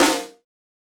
Snare 001.wav